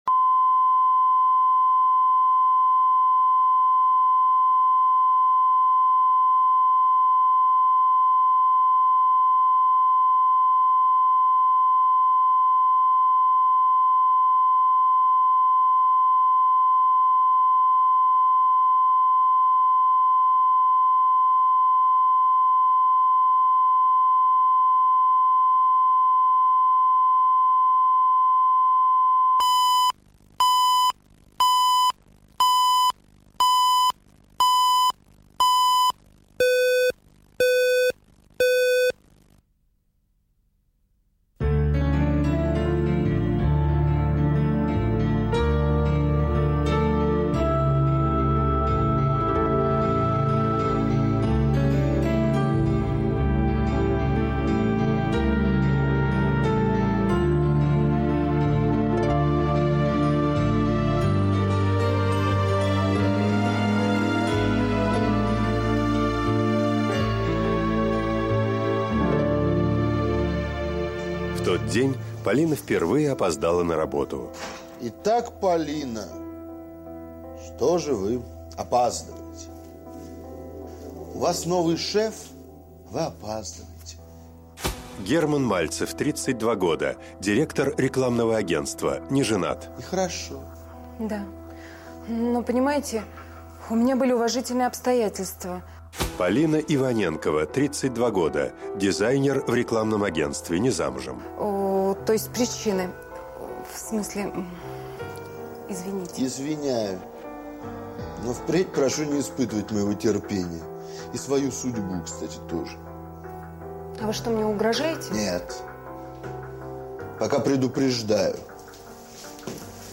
Аудиокнига У кого четыре глаза | Библиотека аудиокниг